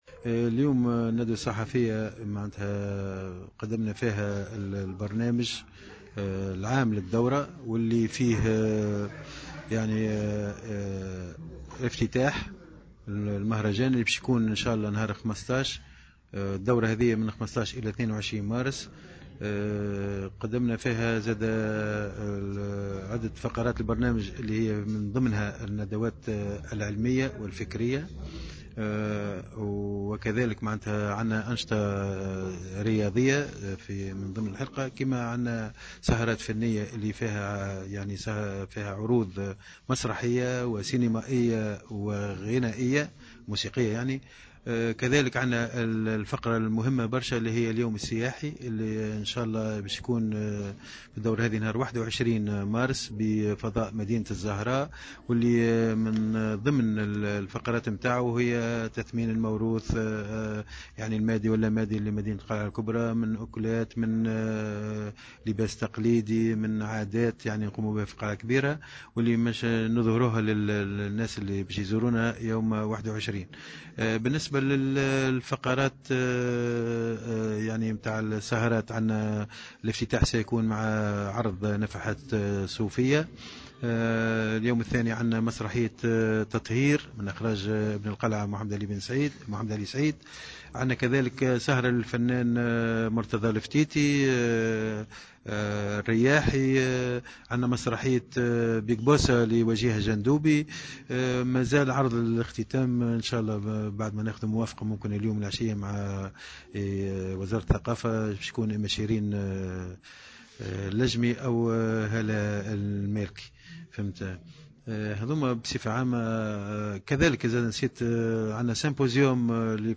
خلال ندوة صحفية انتظمت اليوم بمقر بلدية القلعة الكبرى